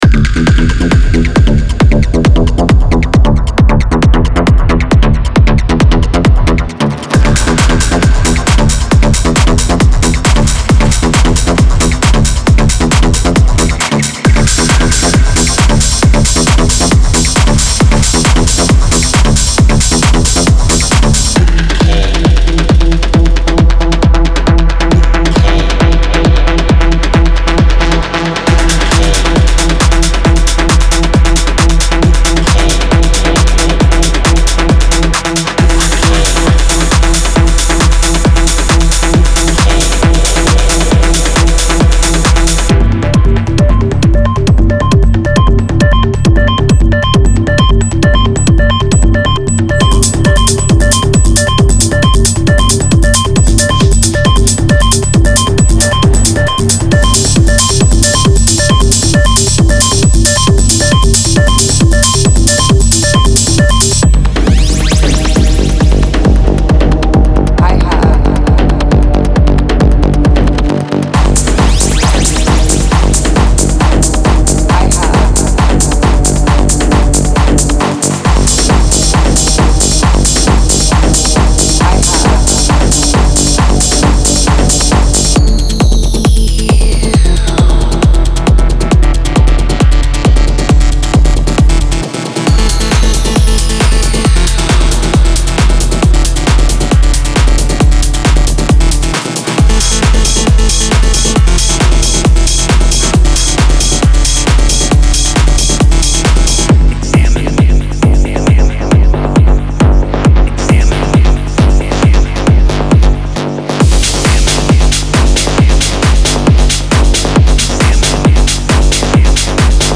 raw techno sample pack
Raw, rough, dirty!
24 bit WAV Stereo
120 One Shot (Clap, Hat, Kick, Perc)
MP3 DEMO